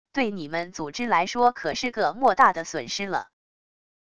对你们组织来说可是个莫大的损失了wav音频生成系统WAV Audio Player